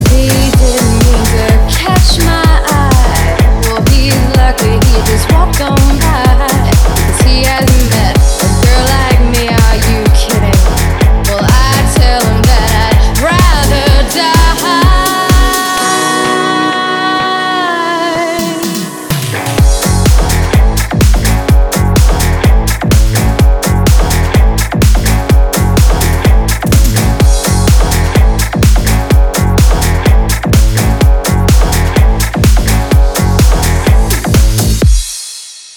• Качество: 320, Stereo
женский голос
Club House
клубняк
Стиль: club house